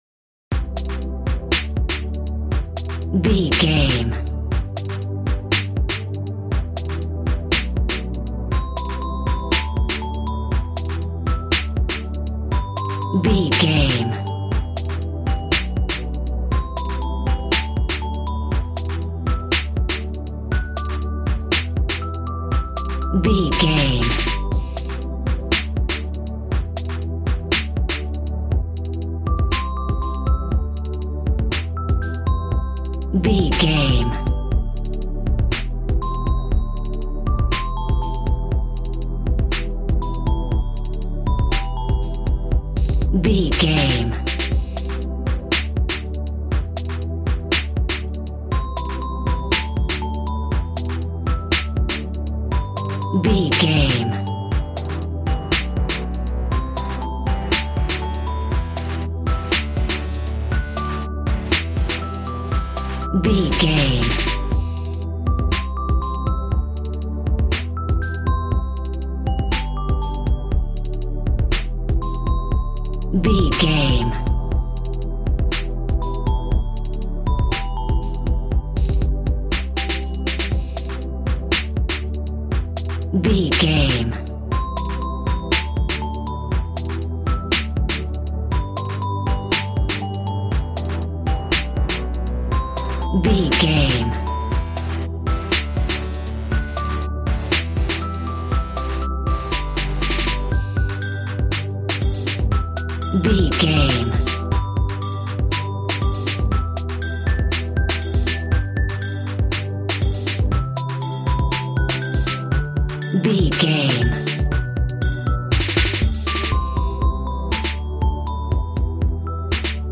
Aeolian/Minor
Fast
groovy
synthesiser
drums
cool
piano